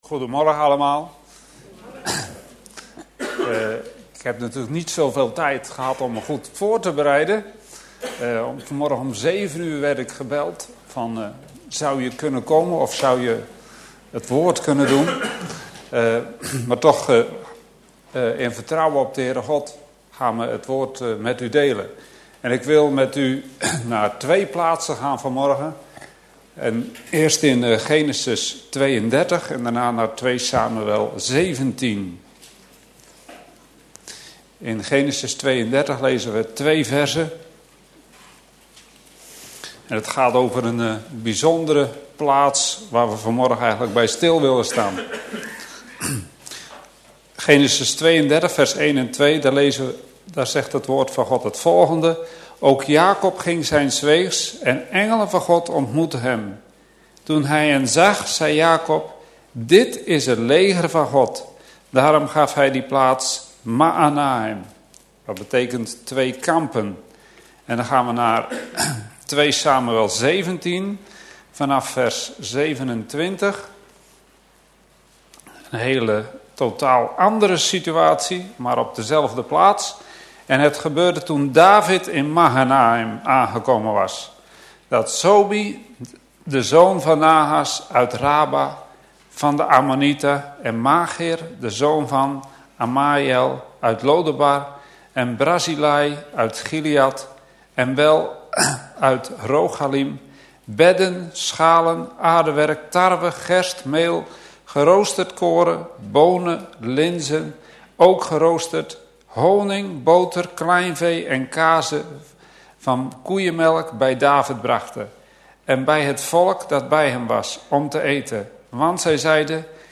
In de preek aangehaalde bijbelteksten (Statenvertaling)Genesis 32:1-21 Jakob toog ook zijns weegs; en de engelen Gods ontmoetten hem. 2 En Jakob zeide, met dat hij hen zag: Dit is een heirleger Gods! en hij noemde den naam derzelver plaats Mahanaim.